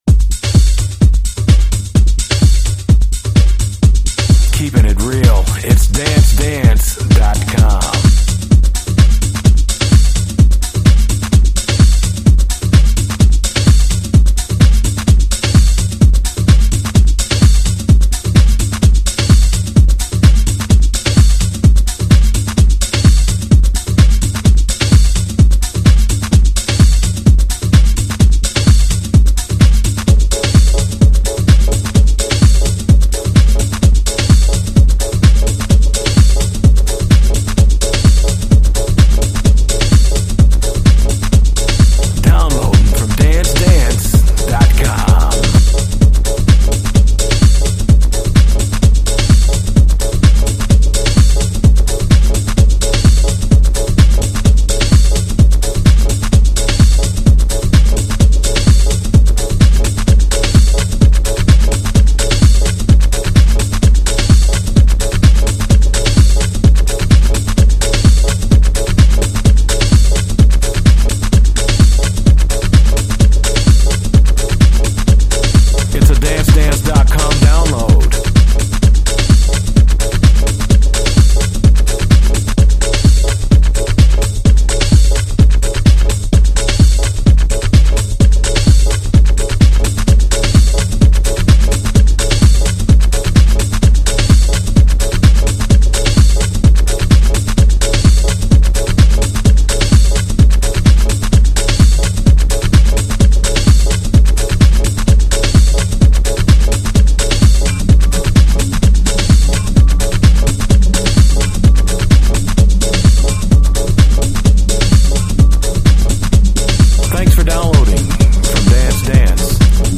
tech-house